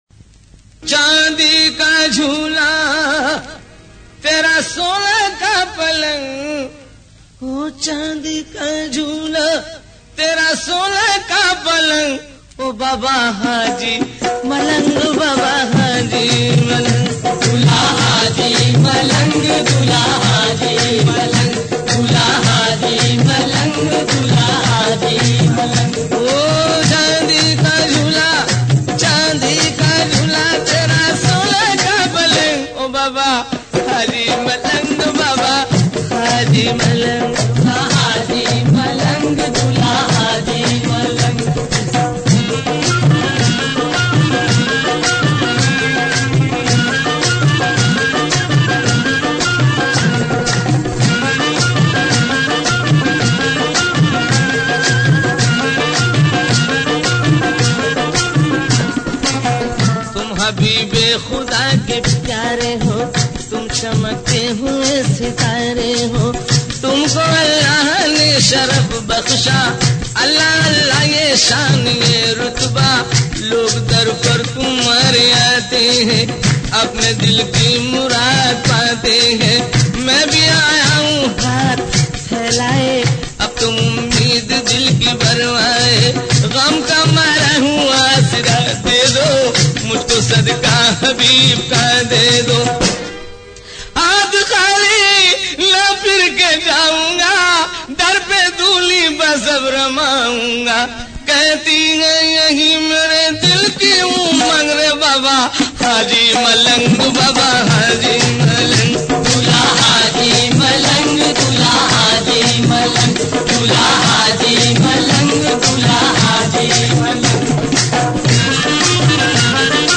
Islamic Qawwalies And Naats > Dargahon Ki Qawwaliyan